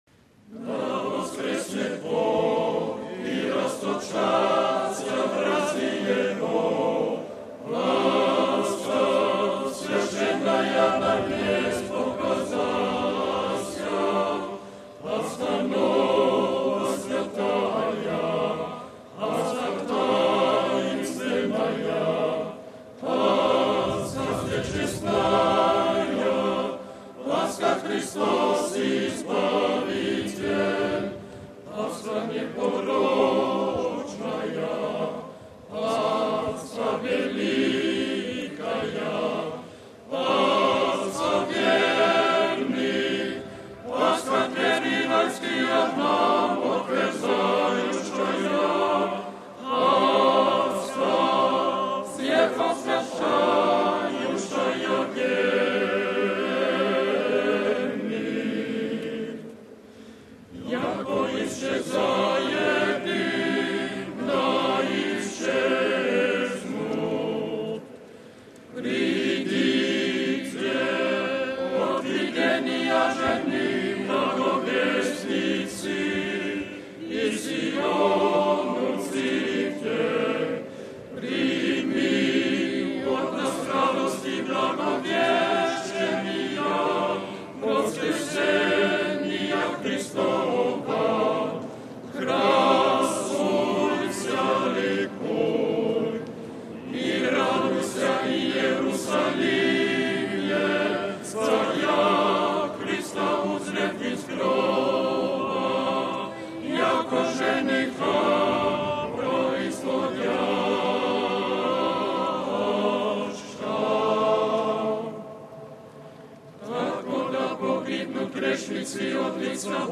PETRA (nedelja, 20. april 2014, RV) – Papež Frančišek je na Trgu sv. Petra daroval sveto mašo Gospodovega vstajenja. Ker letos spet sovpada praznovanje velike noči po gregorijanskem in julijanskem koledarju, ki se ga držijo mnoge pravoslavne Cerkve, je po evangeliju zadonel spev stihov in stiher iz bizantinskega bogoslužja.
Audio stihov s Trga sv. Petra: RealAudio